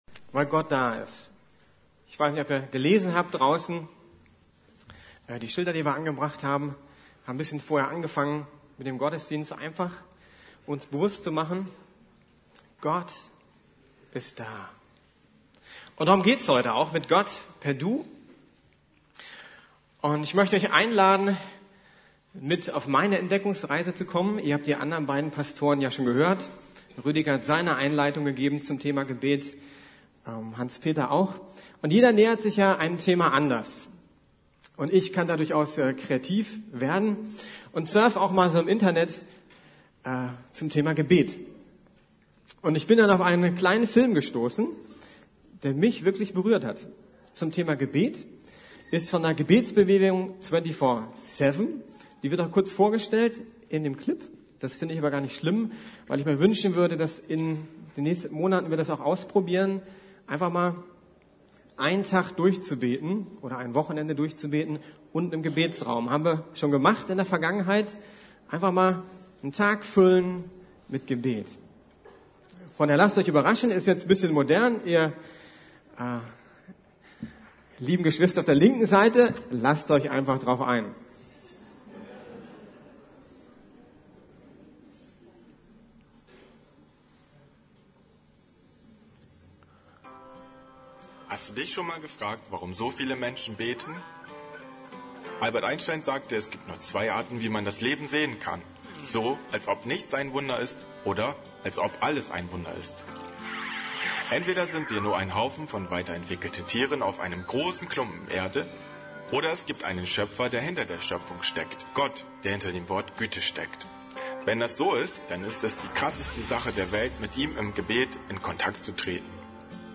Mit Gott per Du! ~ Predigten der LUKAS GEMEINDE Podcast